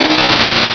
pokeemerald / sound / direct_sound_samples / cries / silcoon.aif